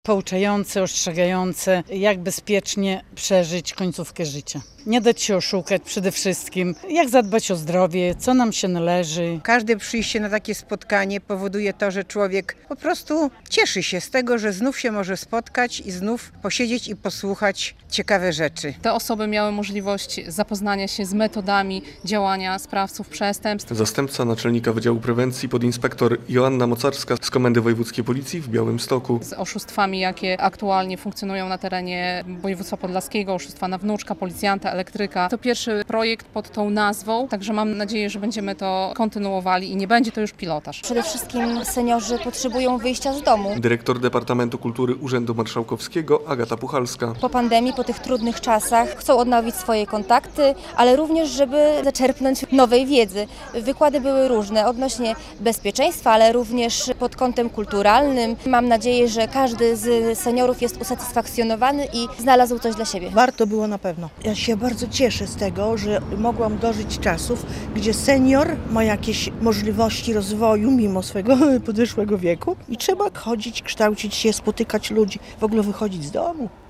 Uniwersytet Bezpiecznego Seniora - relacja